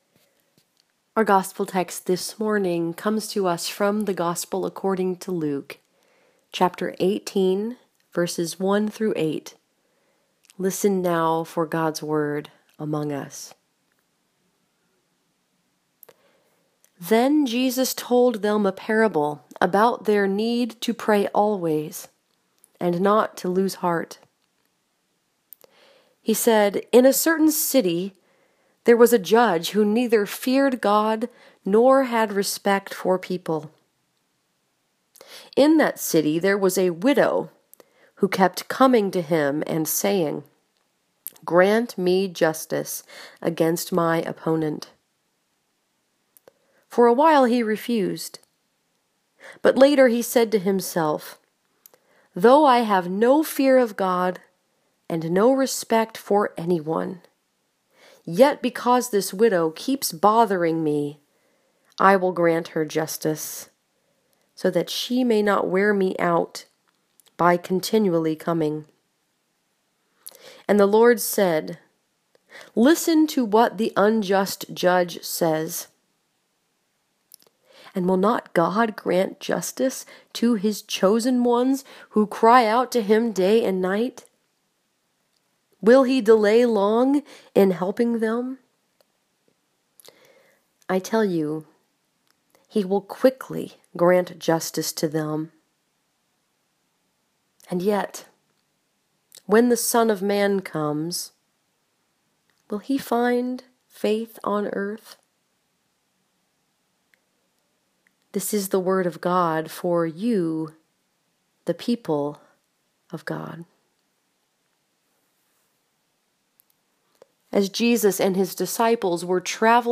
This sermon was preached at Peoples Presbyterian Church in Milan, Michigan and was focused upon Luke 18:1-8 The audio recording is above and a written manuscript is below.